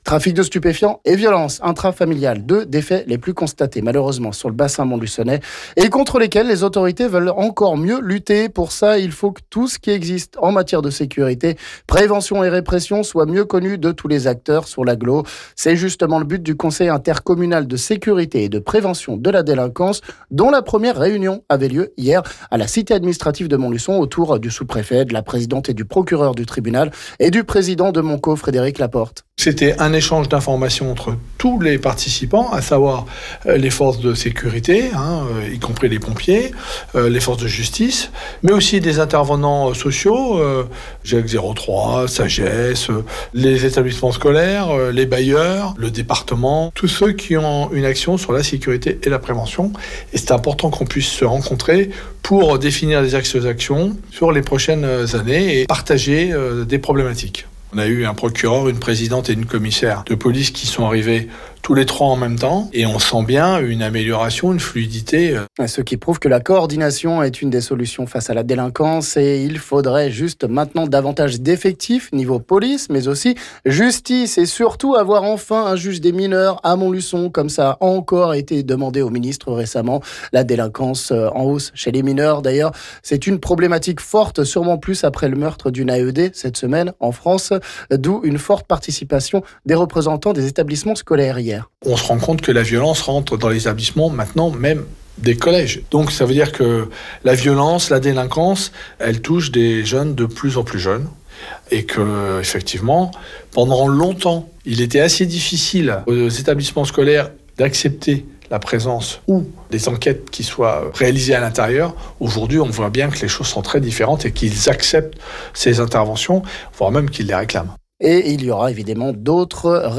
On en parle ici avec le président de Montluçon Communauté Frédéric Laporte...